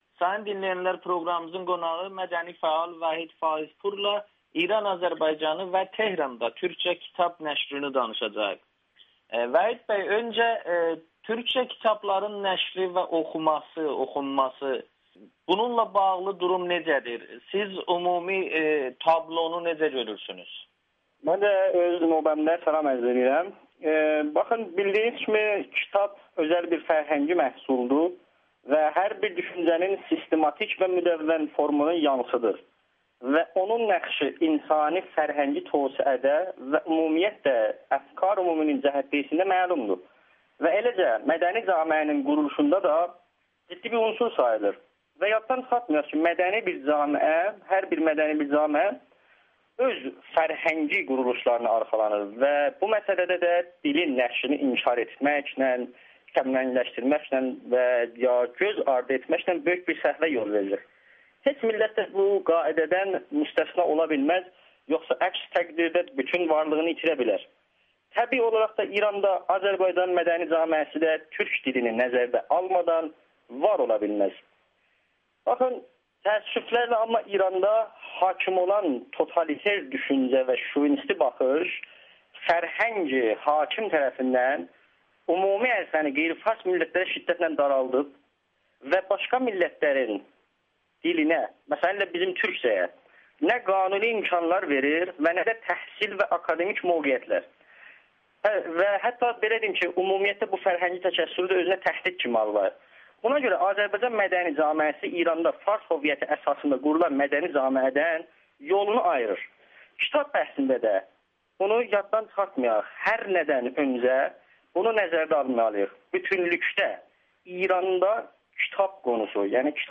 İranda türkcə kitab oxuyanların sayı artmaqdadır [Audio-Müsahibə]